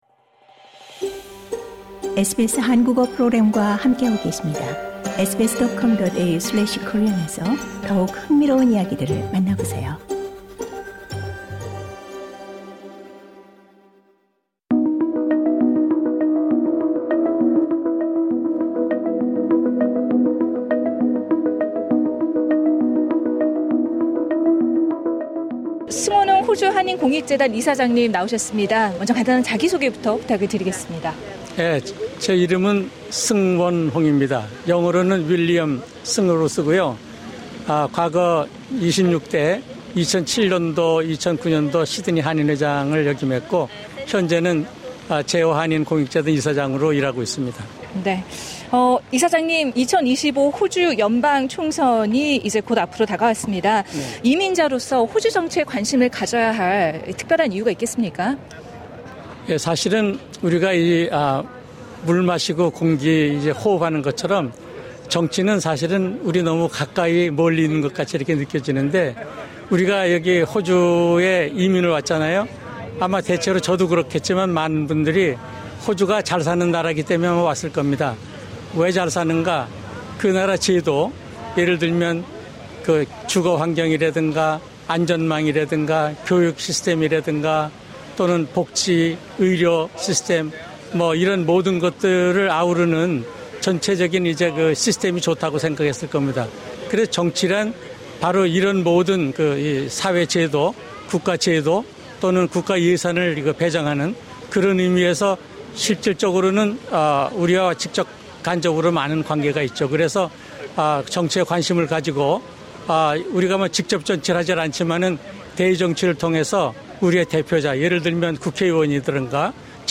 이 가운데 SBS 한국어 프로그램은 지난 4월 24일, 뉴사우스웨일스(NSW)주 파라마타 센테너리 스퀘어(Centenary Square)에서 열린 행사에 참가해, 한인 밀집 지역인 파라마타(Parramatta)와 리드(Reid) 지역구에 출마한 후보자들을 만났습니다.